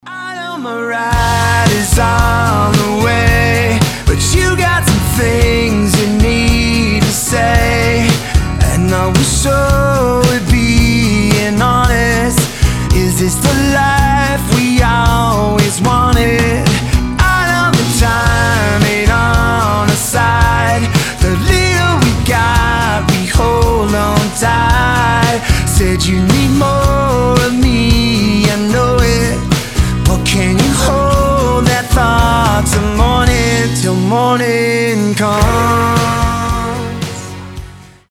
• Качество: 320, Stereo
гитара
Alternative Rock
классический рок